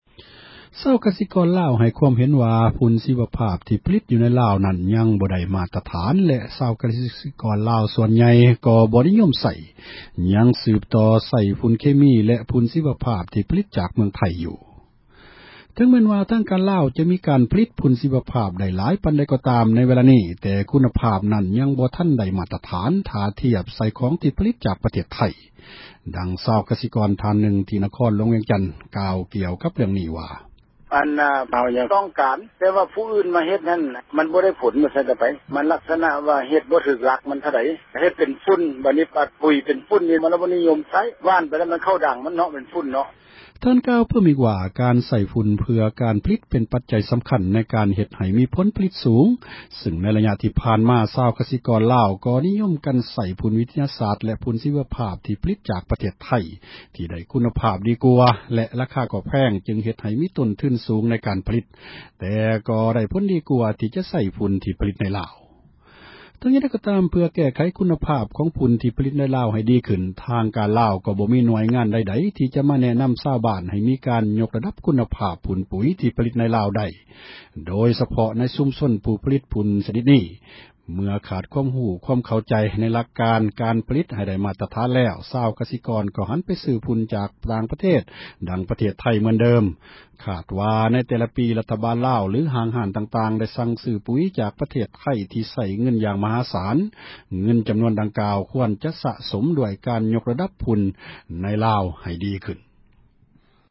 ເຖິງແມ່ນວ່າ  ທາງການລາວ ຈະມີ ການຜລິດ ຝຸ່ນຊີວະພາບ ໄດ້ຫລາຍ ປານໃດ ກໍ່ຕາມ ໃນ ເວລານີ້, ແຕ່ ຄຸນນະພາບ ນັ້ນ ຍັງບໍ່ທັນ ໄດ້ມາຕຖານ ເທົ່າທຽບ ກັບ ຂອງ ທີ່ຜລິດ ຈາກ ປະເທດໄທ, ດັ່ງ ຊາວກະກອນ ທ່ານ ນຶ່ງ ທີ່ ແຂວງວຽງຈັນ ກ່າວ ກ່ຽວກັບ ເຣື້ອງນີ້ ວ່າ: